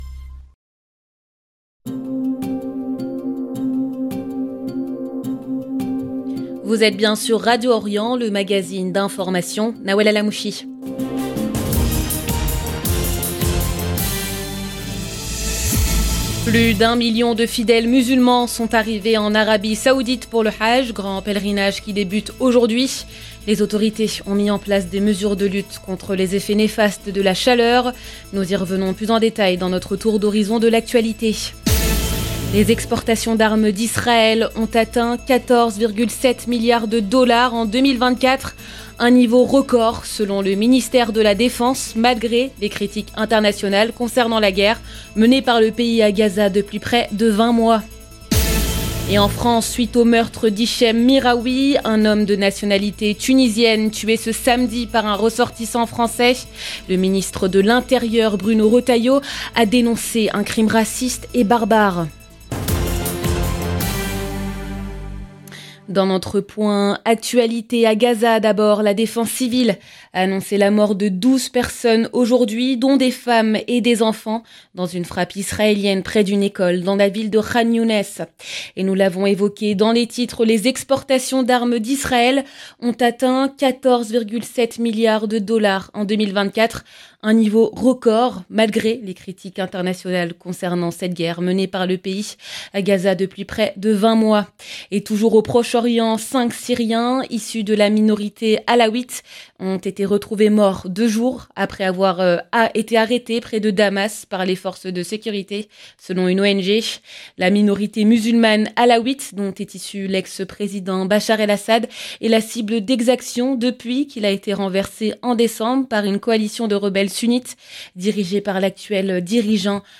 Magazine de l'information de 17H30 du mercredi 4 juin 2025